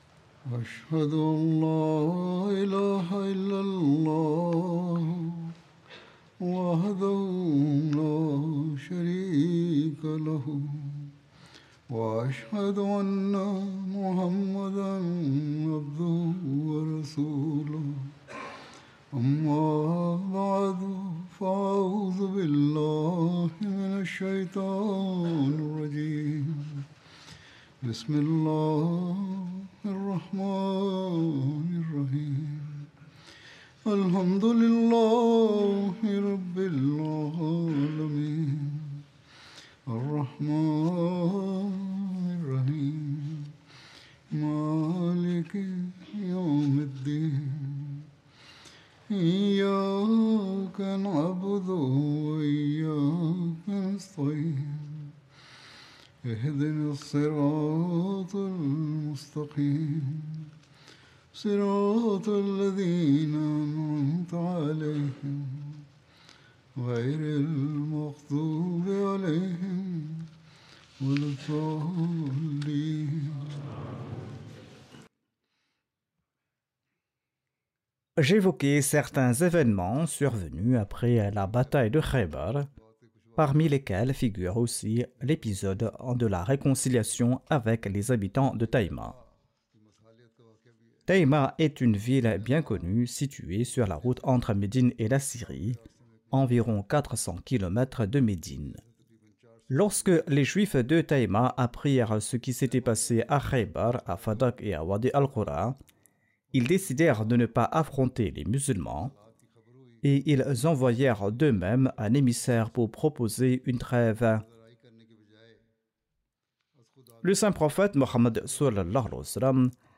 French translation of Friday Sermon delivered by Khalifa-tul-Masih on November 8th, 2024 (audio)